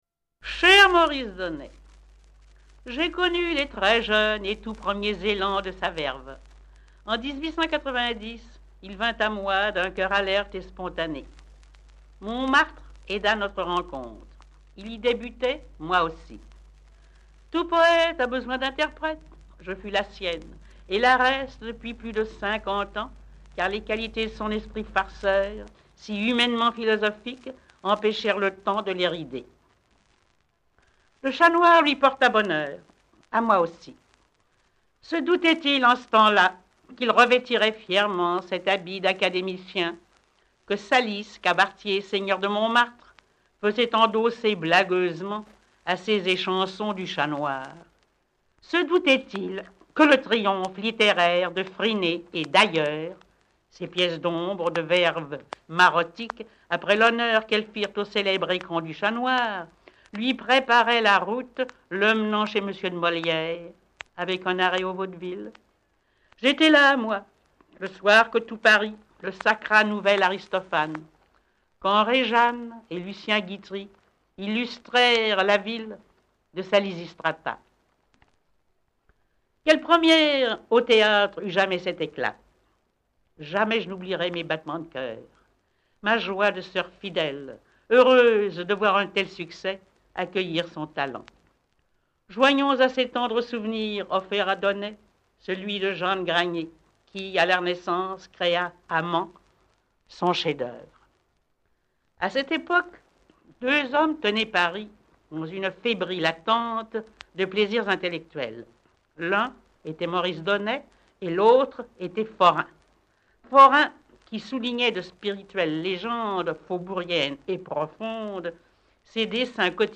Yvette Guilbert